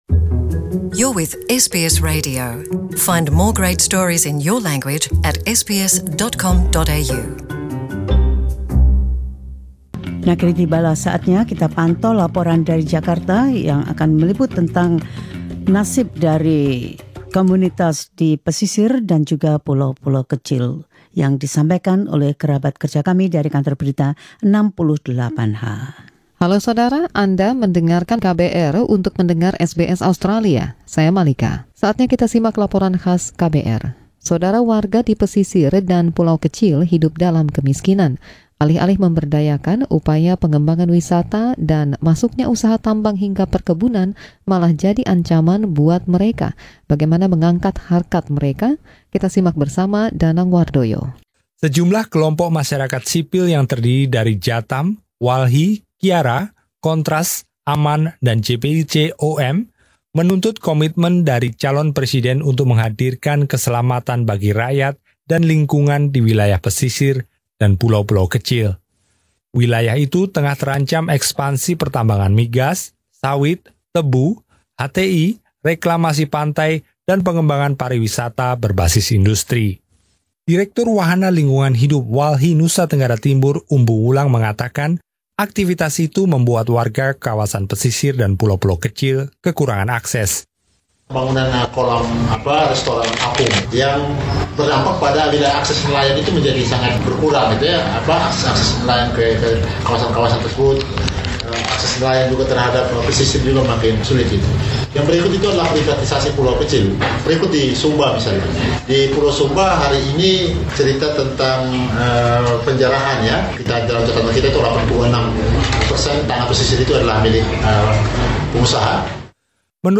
Laporan khusus KBR 68H menyelidiki kurangnya perhatian yang diberikan selama tahun pemilihan ini terhadap nasib nelayan dan masyarakat pesisir yang miskin.